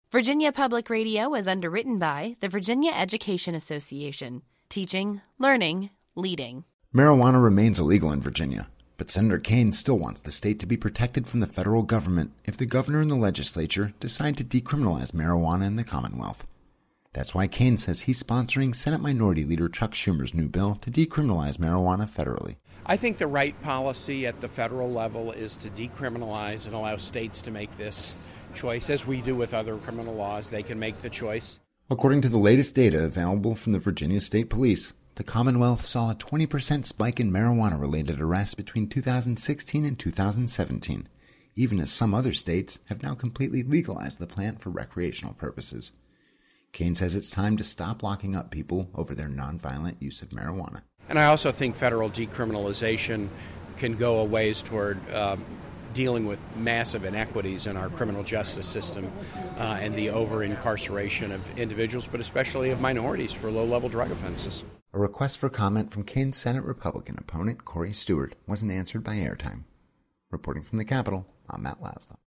has the story from Washington.